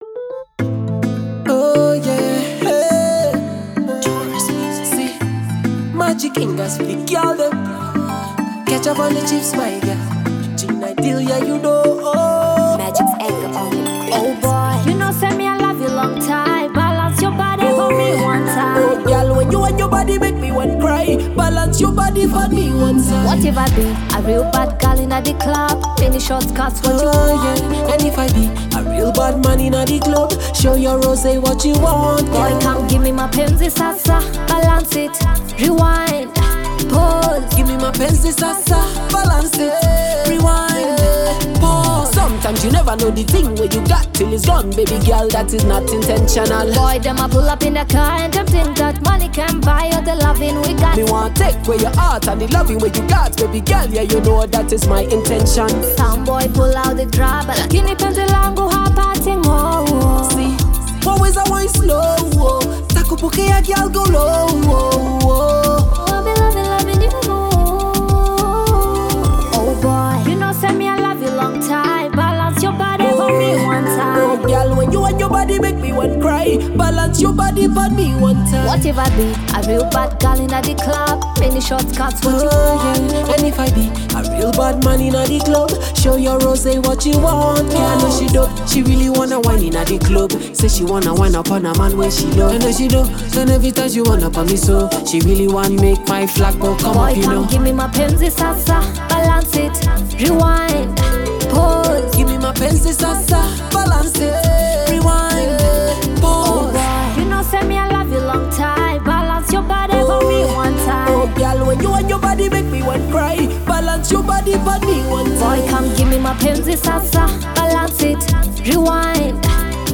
Afro-pop
summer feel good song
summer jam